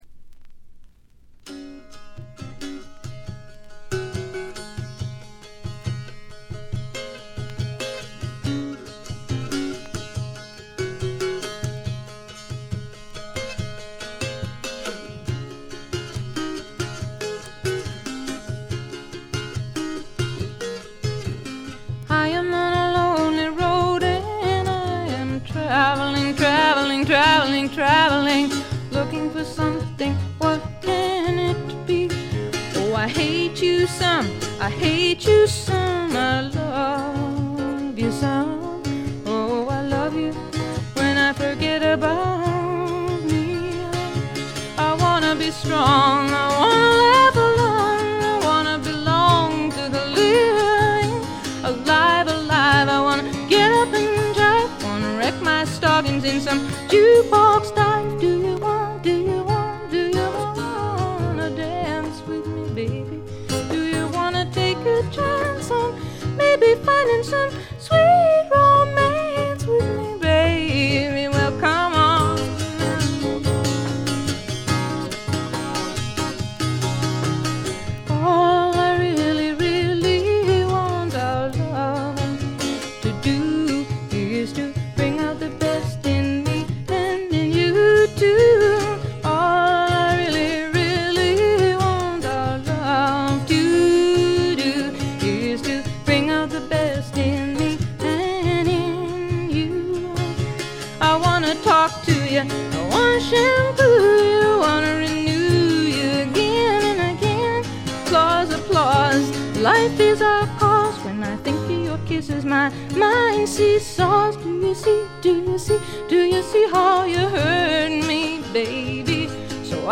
全体にバックグラウンドノイズ。細かなチリプチ多めですが、鑑賞を妨げるほどのノイズはないと思います。
ほとんど弾き語りに近いごくシンプルな演奏が染みます。
試聴曲は現品からの取り込み音源です。